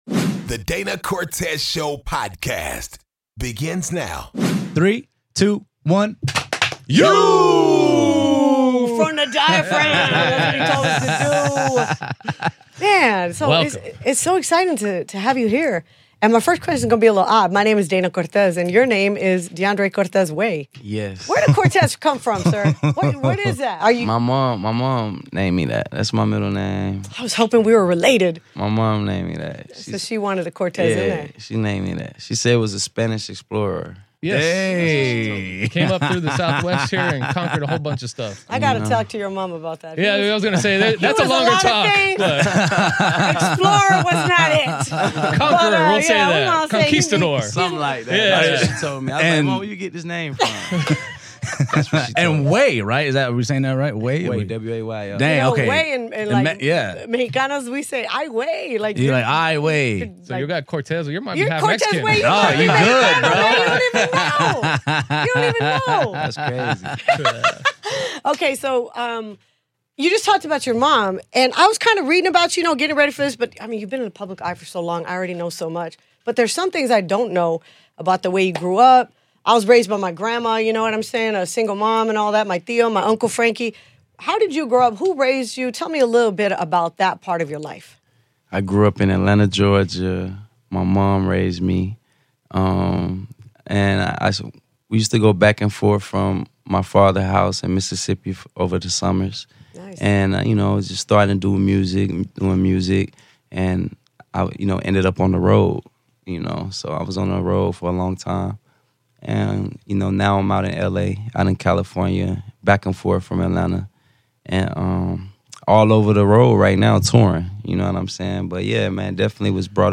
DCS Interviews Soulja Boy